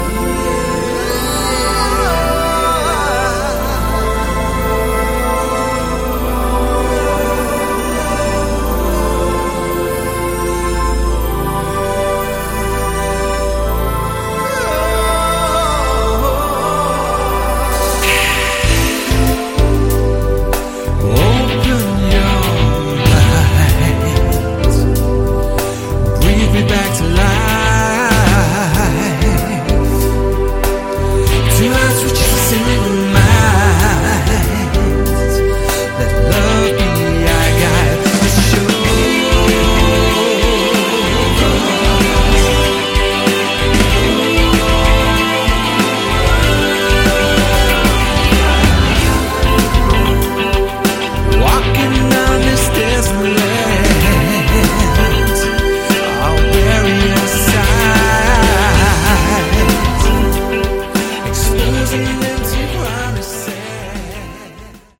Category: AOR
lead and backing vocals, guitar, bass, drums